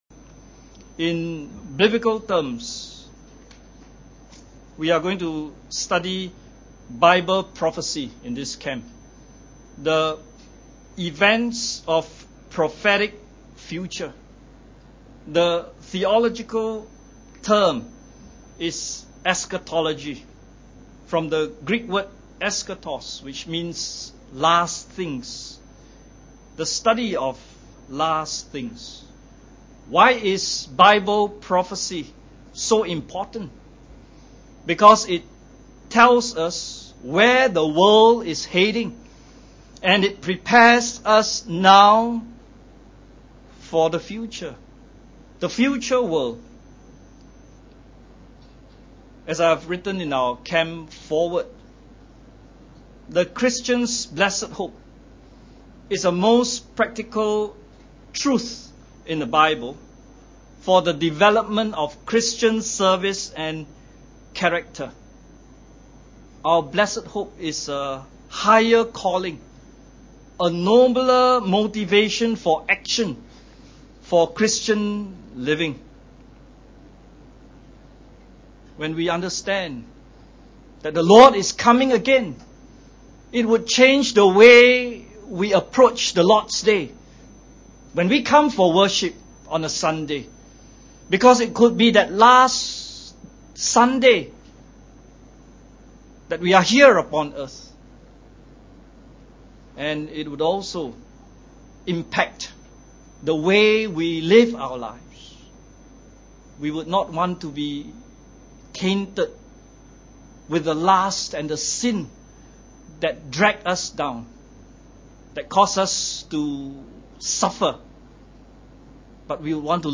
Church Camp 2015 Looking for That Blessed Hope – Redemption (A People of Hope) Message 1